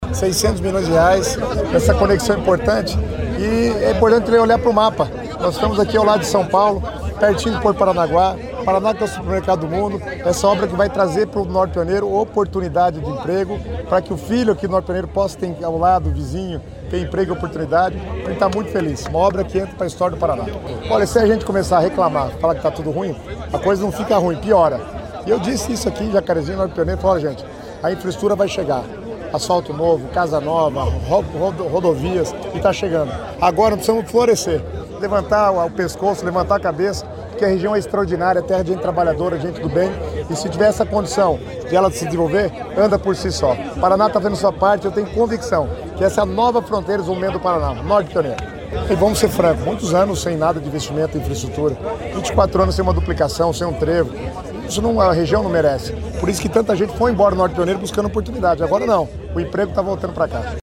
Sonora do secretário das Cidades, Guto Silva, sobre a duplicação da BR-153 entre Jacarezinho e Santo Antônio da Platina